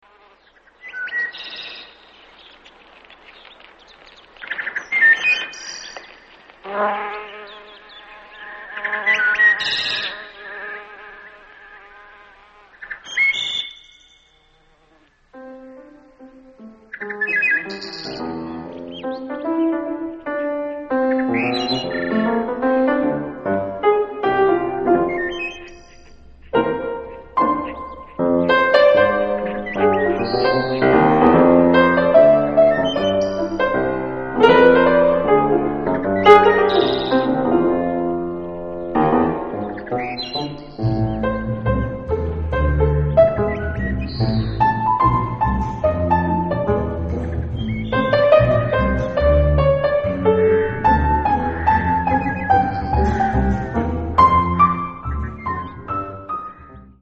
Аудиокнига Музыка леса | Библиотека аудиокниг
Прослушать и бесплатно скачать фрагмент аудиокниги